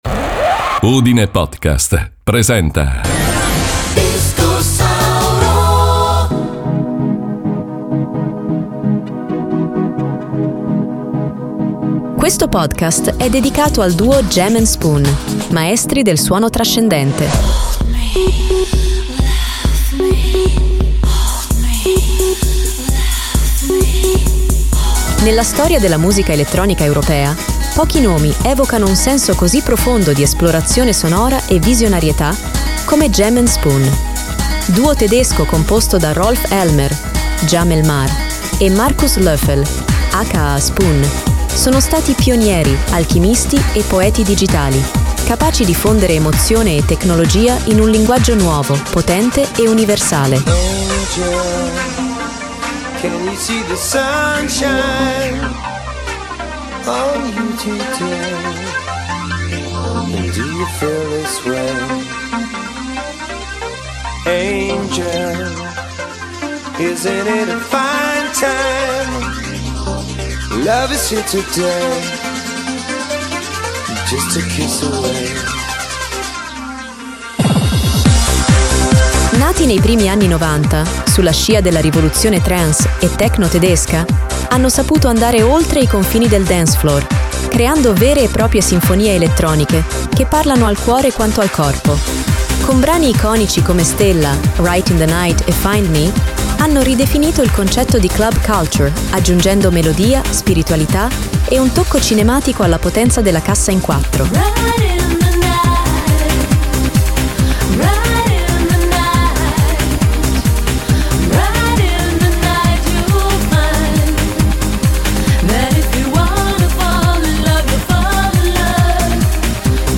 La loro firma sonora è fatta di beat pulsanti e tappeti armonici ipnotici, ma anche di delicatezze eteree, voci sognanti, citazioni orientali e sequenze sintetiche che sembrano provenire da un’altra galassia.